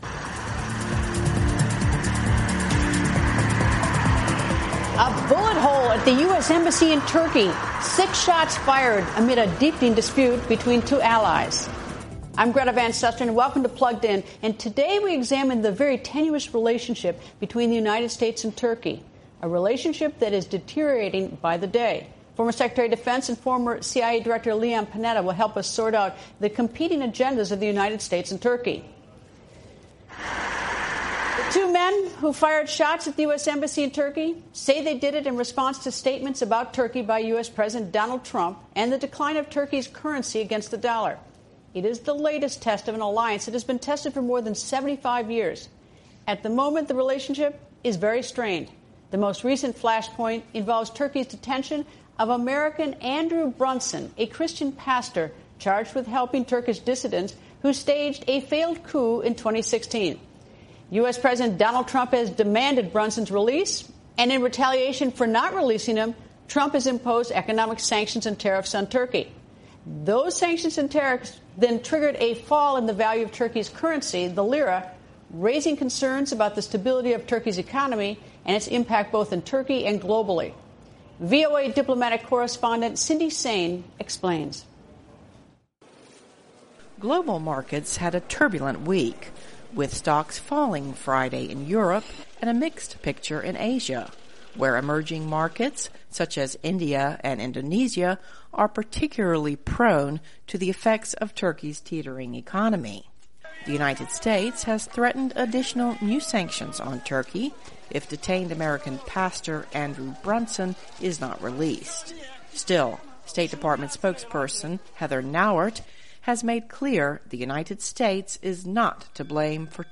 Escalating disputes over trade, Turkey’s detention of an American pastor, and other issues have strained relations between the U.S. and one of its key allies in the region. Greta Van Susteren and her guests discuss whether the relationship can – and should – be saved.